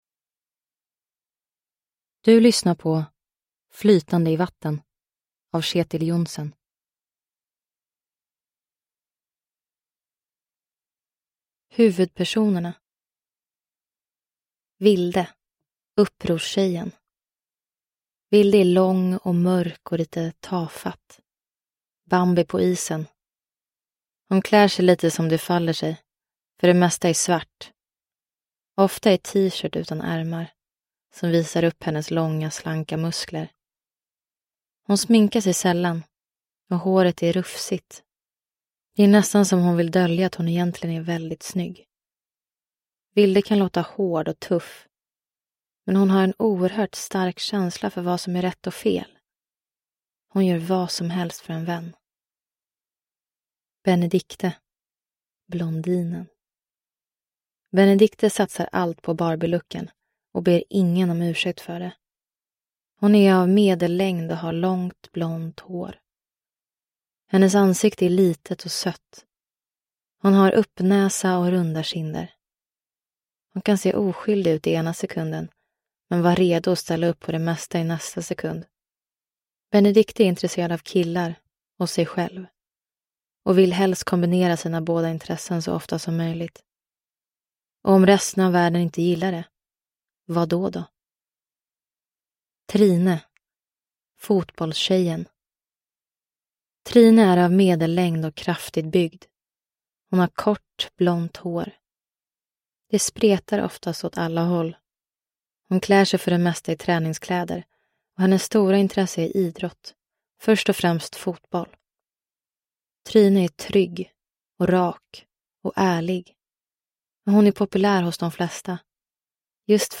Flytande i vatten – Ljudbok – Laddas ner